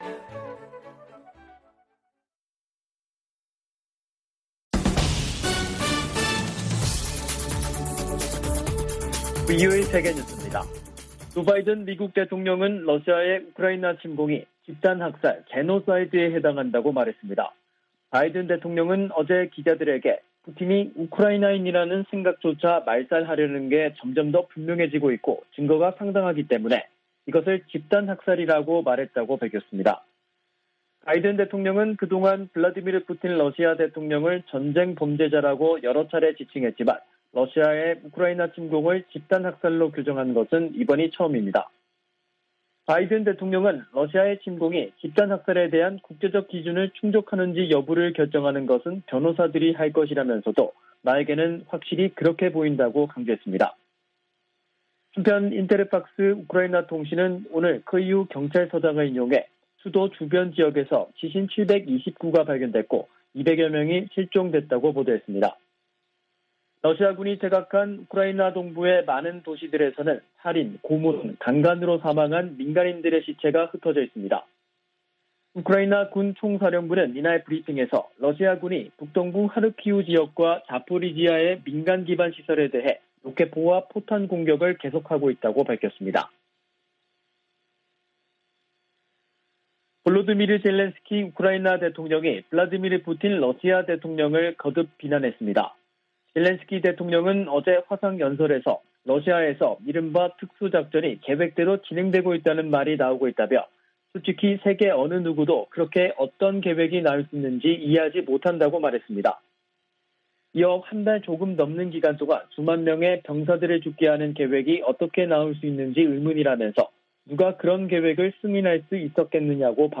VOA 한국어 간판 뉴스 프로그램 '뉴스 투데이', 2022년 4월 13일 2부 방송입니다. 미 국무부는 연례 인권보고서에서 북한이 세계에서 가장 억압적이고 권위주의적인 국가라고 비판했습니다. 미 국방부는 북한이 전파 방해와 같은 반우주역량을 과시하고 있으며, 탄도미사일로 인공위성을 겨냥할 수도 있다고 평가했습니다. 한국 윤석열 차기 정부의 초대 외교부와 통일부 장관에 실세 정치인들이 기용됐습니다.